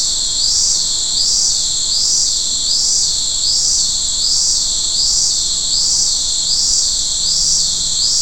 Afternoon cicadas also have a loud droning tymbalization, but Blue-eared Barbets don’t mind co-chorusing with these cicadas because they use non-overlapping frequency bands. The afternoon cicada uses frequencies 3.5 – 7.5 kHz, and the Blue-eared Barbet uses frequencies 1.5 – 2.5 kHz.